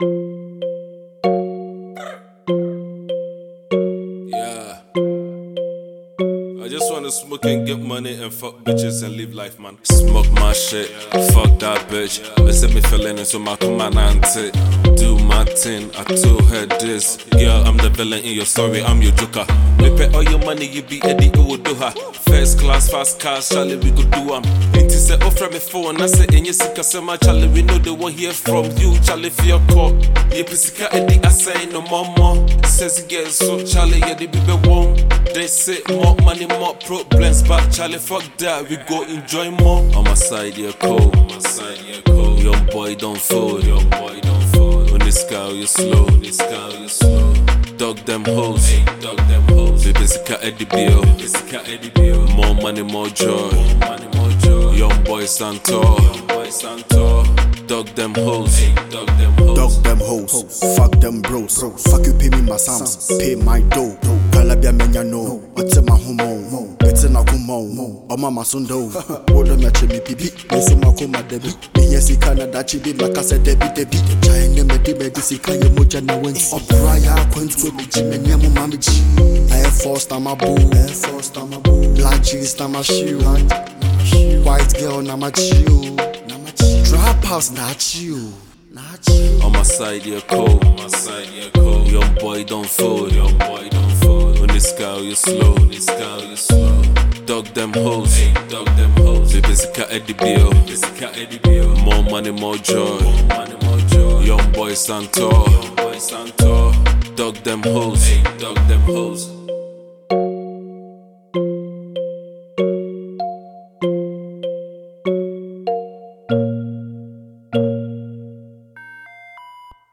a Ghanaian trapper